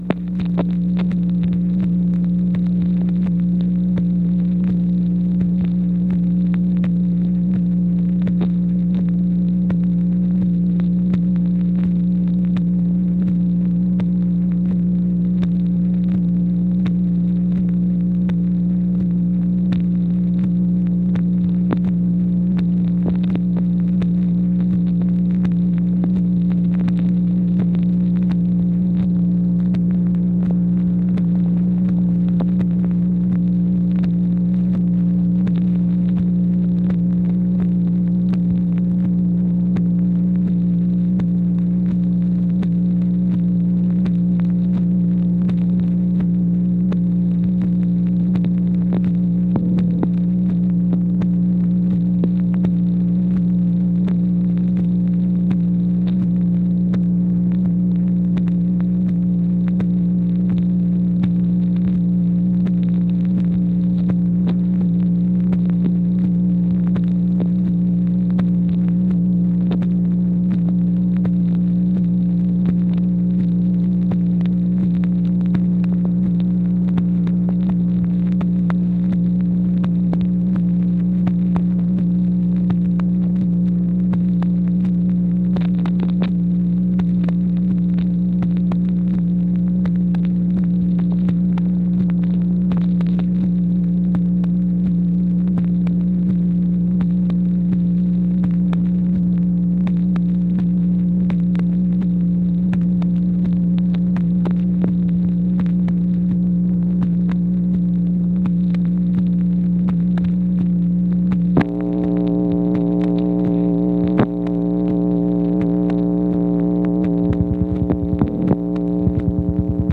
MACHINE NOISE, July 10, 1964
Secret White House Tapes | Lyndon B. Johnson Presidency